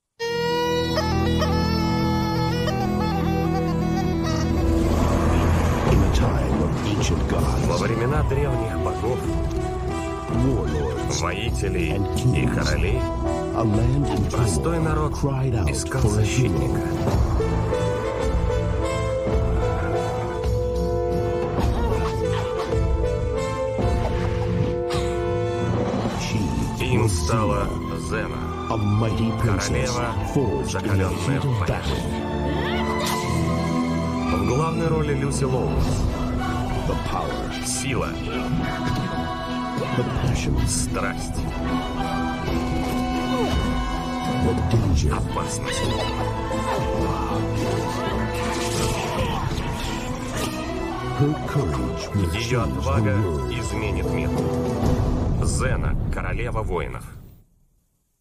Звук интро заставки